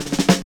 44 SN BUZZ-L.wav